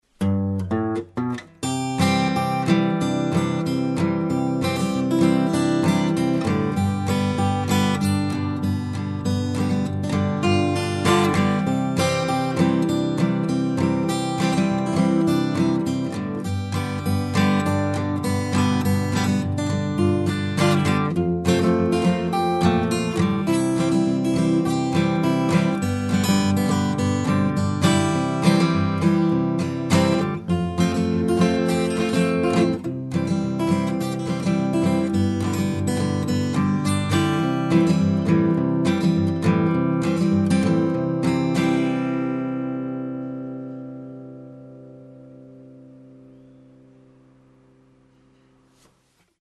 Detta är endast ett exempel på hur ”Little Bus Goes South” kan låta när man även använder sig av ”strumming” i låten (när man drar över strängarna).
Fingerstyle 5.mp3